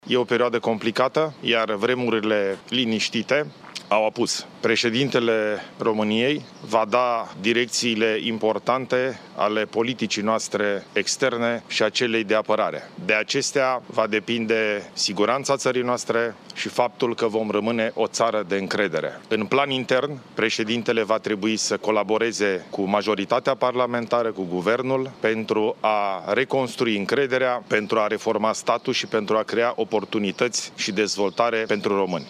Președintele interimar al României, Ilie Bolojan, a votat în această dimineață, la o secție de votare din apropierea Palatului Cotroceni. Ilie Bolojan a atras atenția că este o perioadă complicată și că siguranța țării depinde de președintele României.